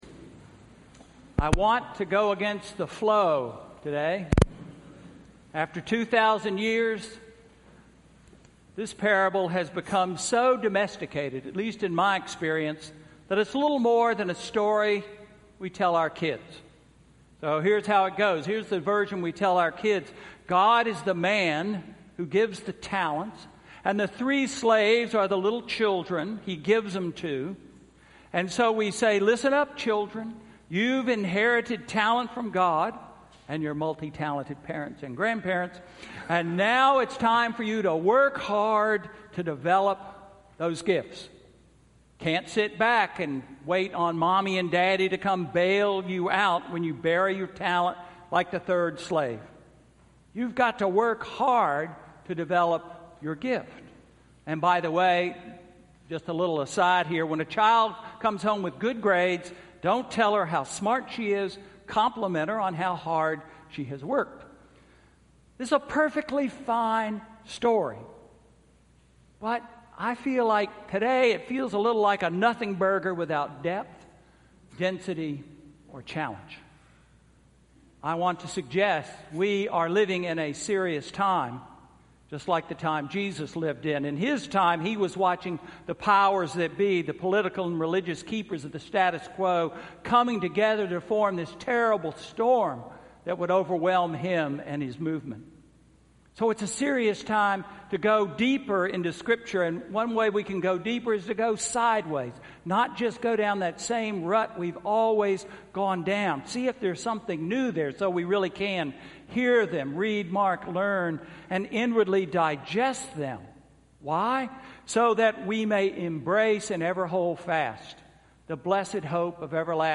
Sermon–November 19, 2017